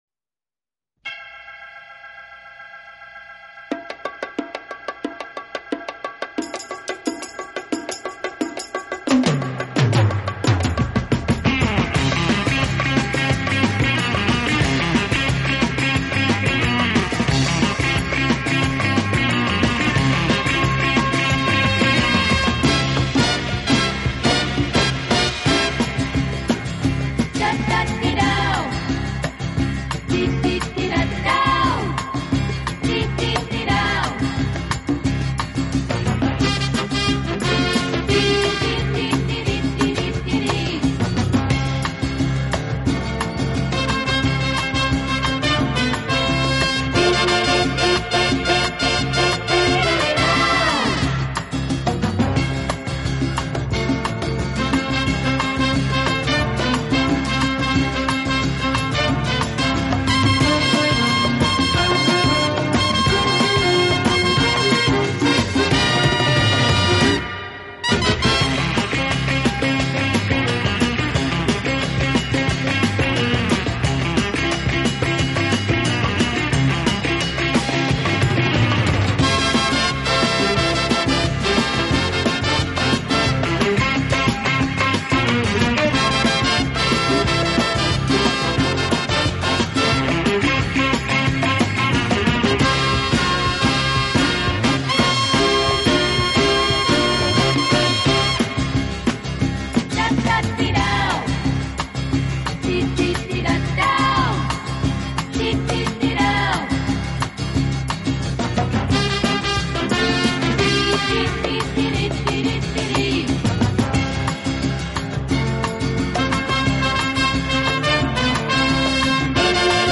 Genre: instrumental/Oldies
轻音乐作品的旋律优美动听、清晰流畅，节奏鲜明轻快，音色丰富多彩，深受